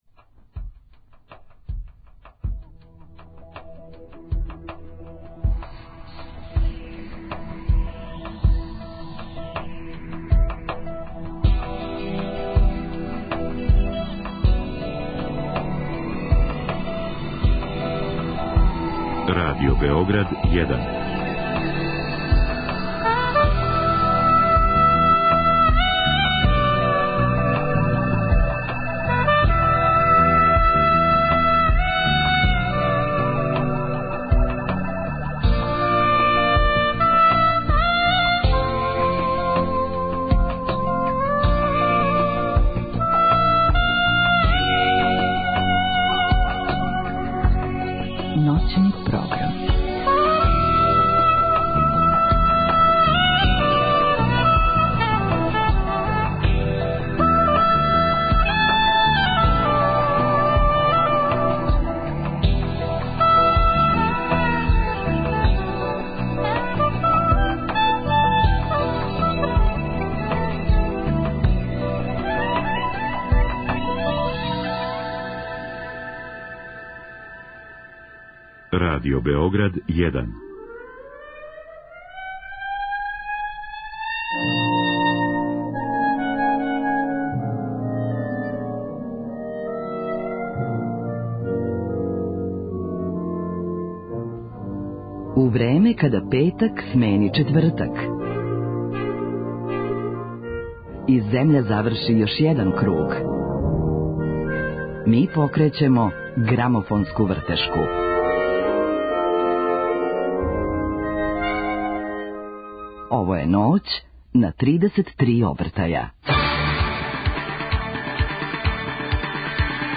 У ноћи на 33 обртаја слушамо неке од најзначајнијих плоча са екс Ју простора.